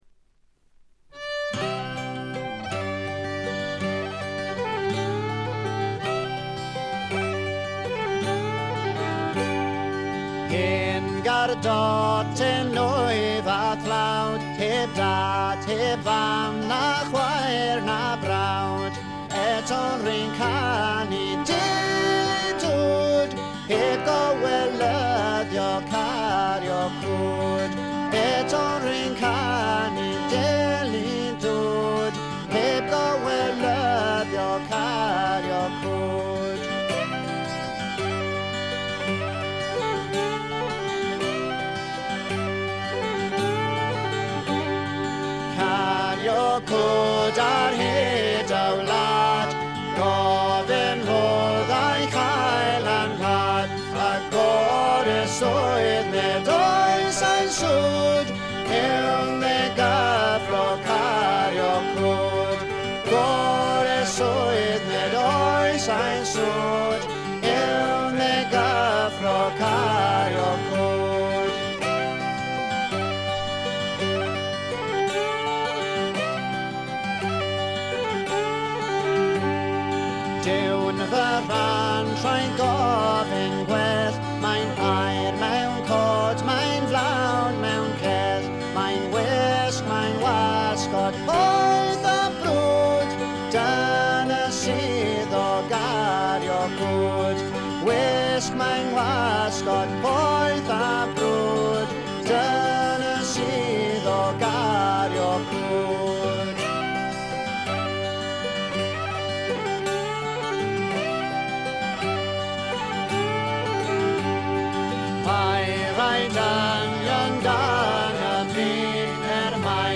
微細なチリプチ少々。
ウェールズのトラッド・グループ
試聴曲は現品からの取り込み音源です。
Vocals
Guitar
Mandolin
Fiddle